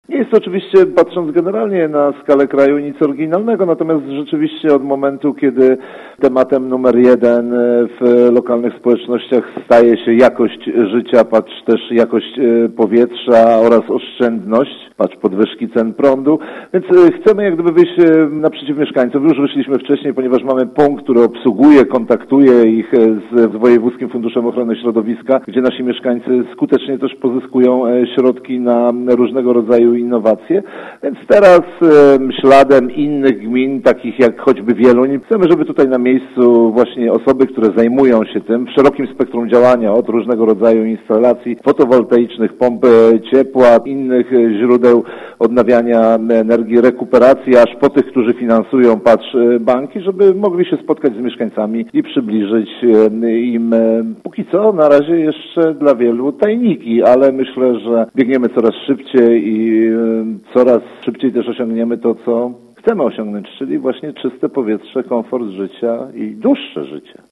– mówił burmistrz Wieruszowa, Rafał Przybył.